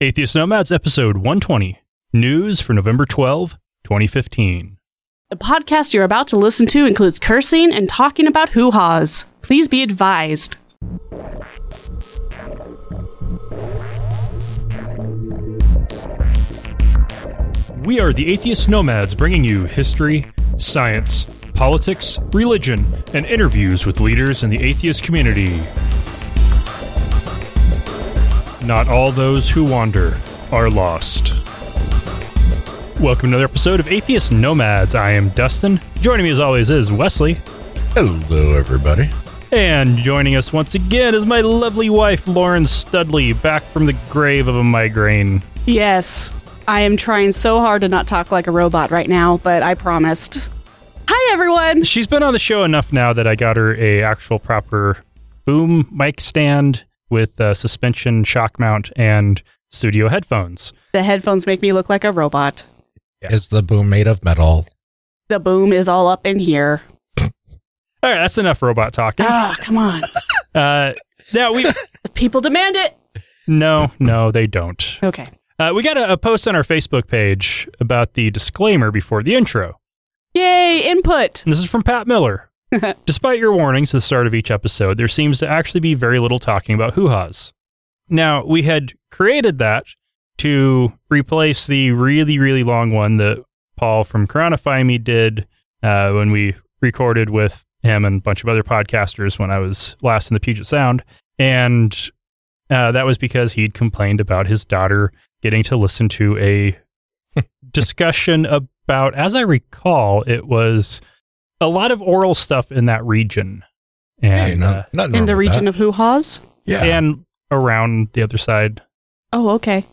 The podcast you’re about to listen to includes cursing and talking about huhas.